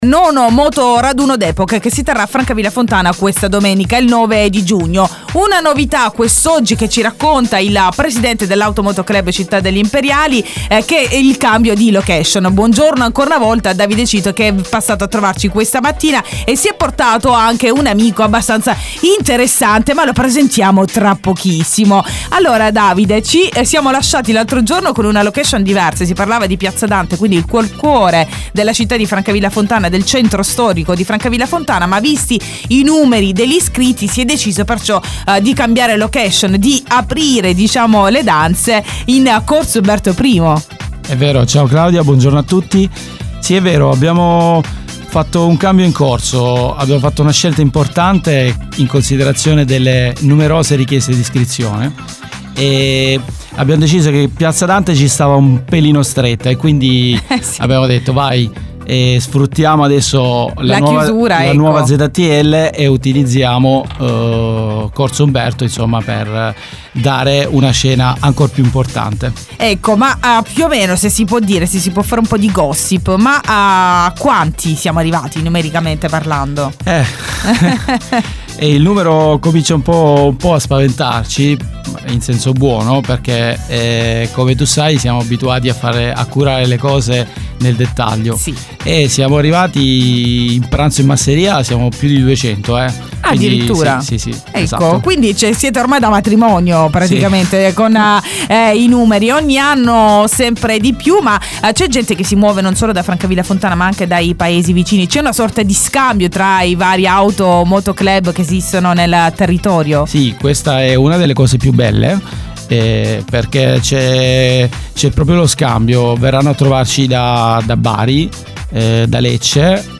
Domenica 9 giugno nona edizione del raduno di moto d’epoca organizzato dall’Auto moto club Città degli Imperiali , affiliato ACI storico. In studio